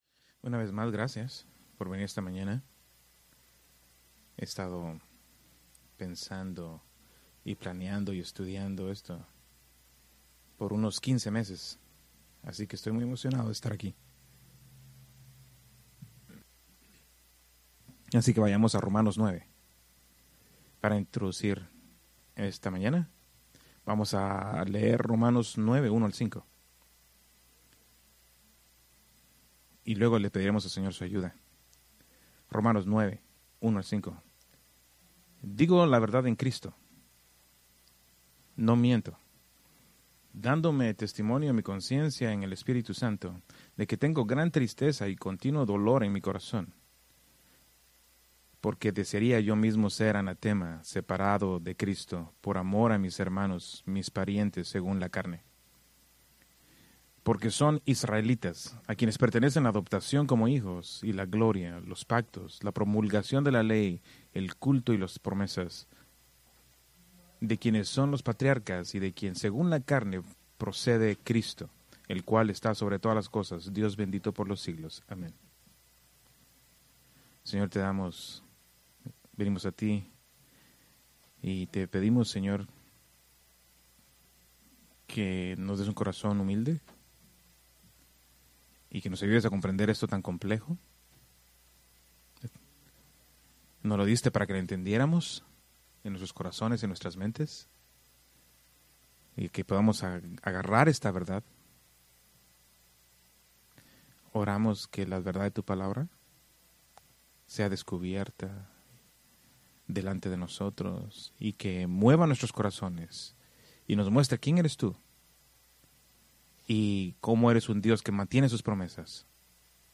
Preached April 18, 2026 from Romanos 9:1-5